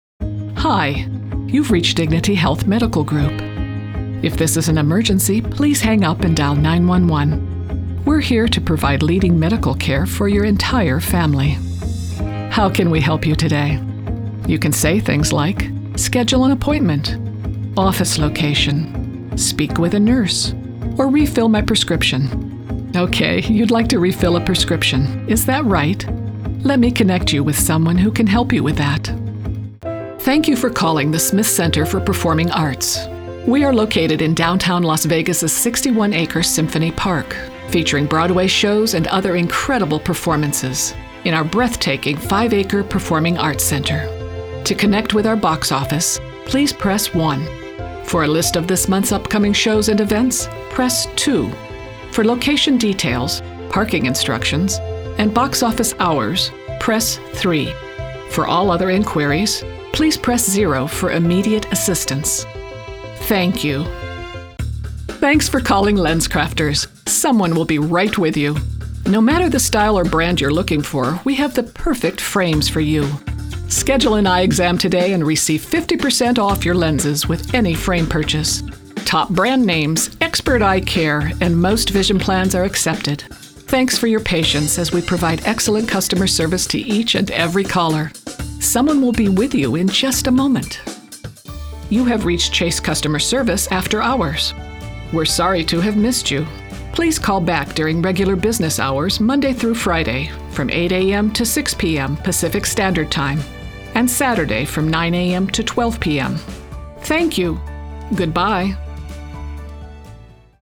U.S. Southern/country; Midwest Neutral
Middle Aged
Senior
Phone System/IVR